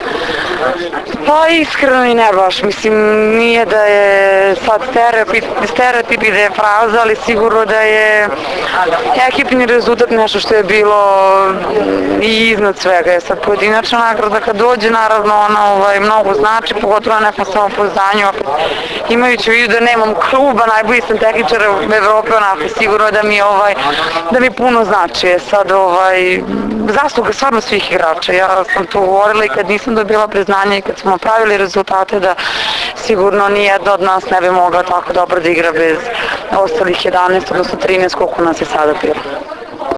IZJAVA MAJE OGNJENOVIĆ 2